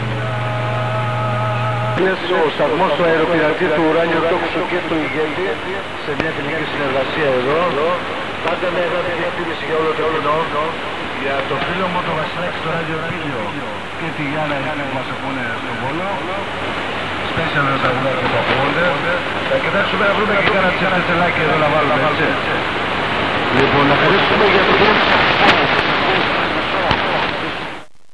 Pirate radio from Greece received in Finland
I have 300 meters longwire directed to South and it gives good signals.